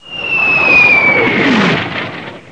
MORTAR.WAV